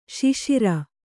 ♪ śiśira